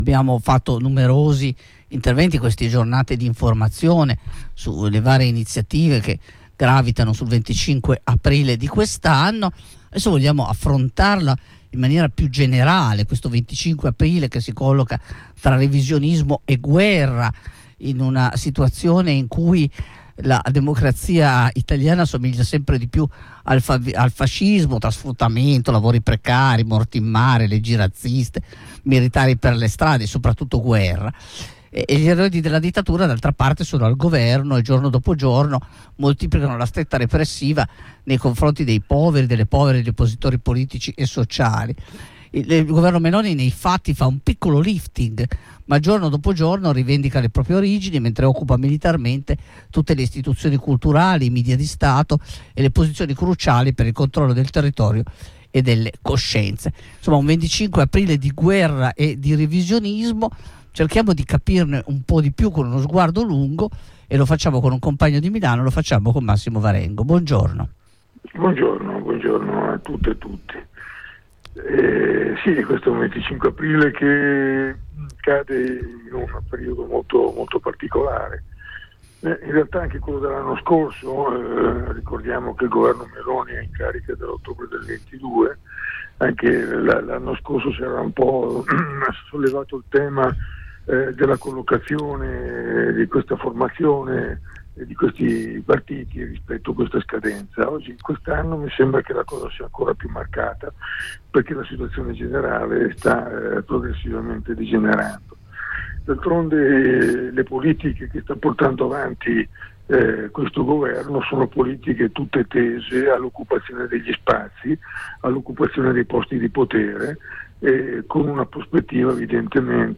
Ne abbiamo parlato con un compagno di Milano